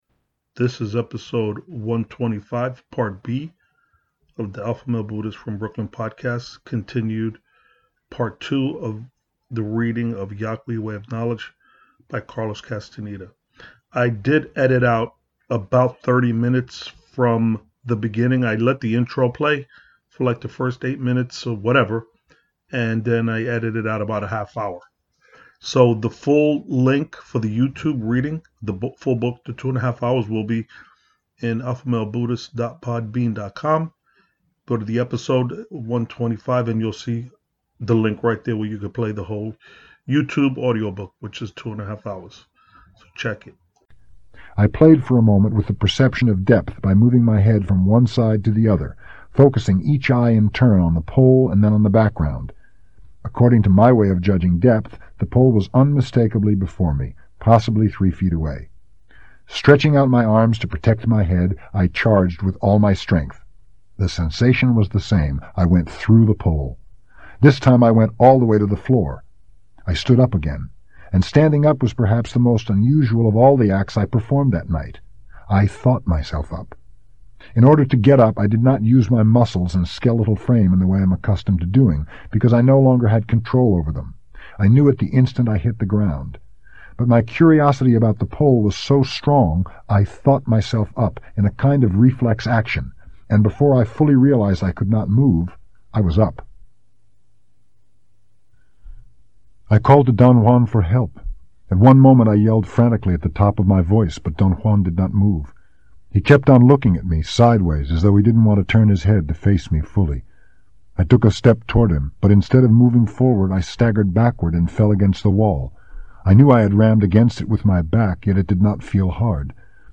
Ep 125 Part B - reading of Carlos Casteneda - Yaqui way of knowledge